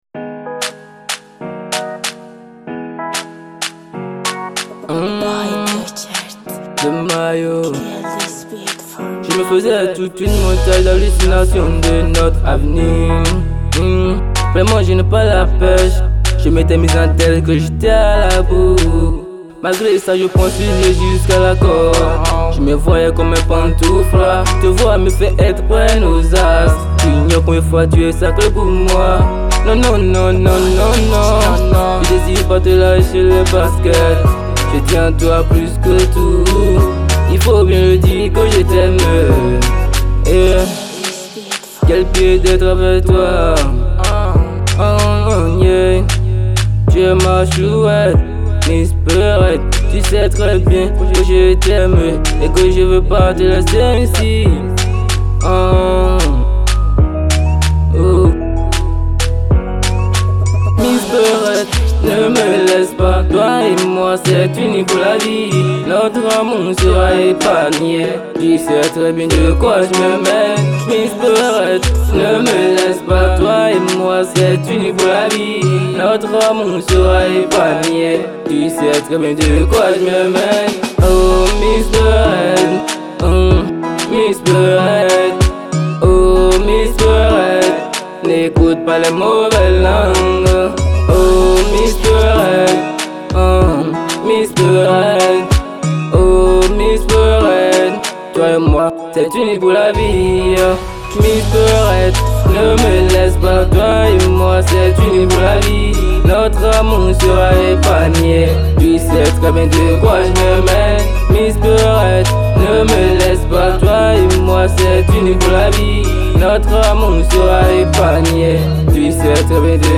Urban Mp3